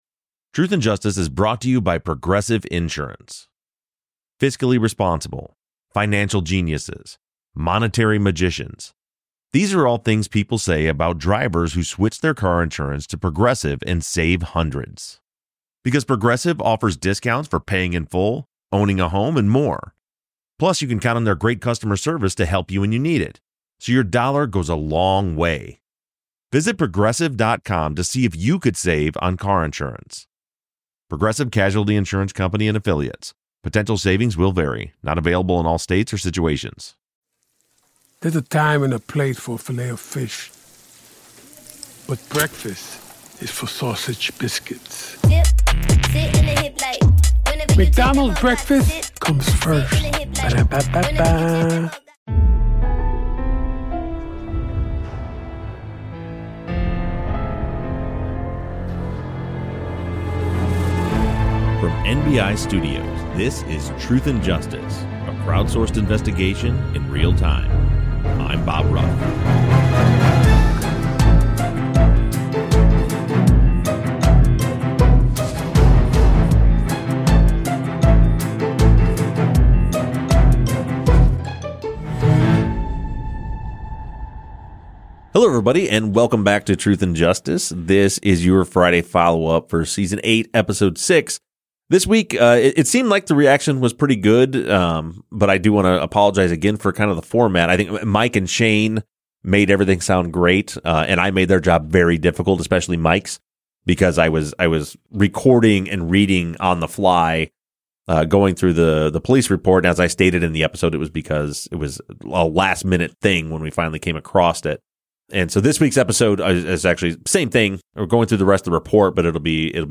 The guys discuss listener questions